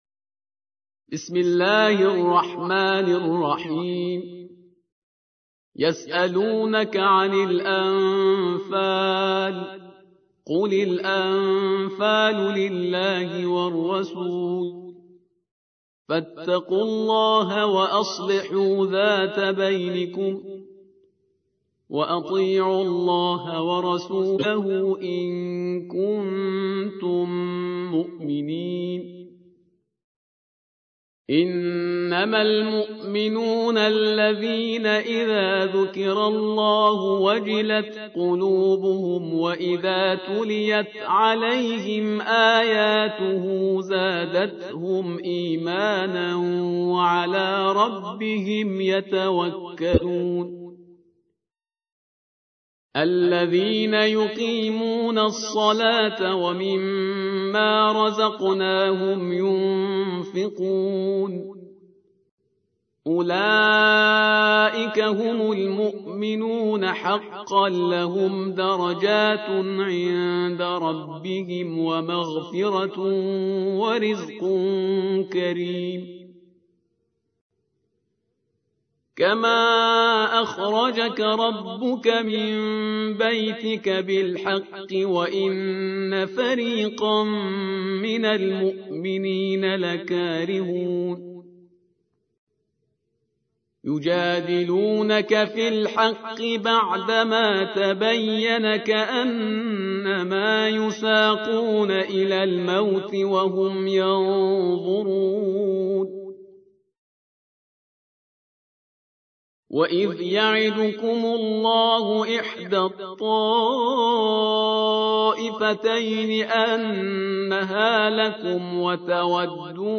تحميل : 8. سورة الأنفال / القارئ شهريار برهيزكار / القرآن الكريم / موقع يا حسين